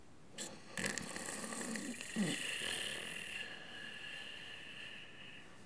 ronflement_05.wav